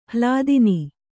♪ hlādini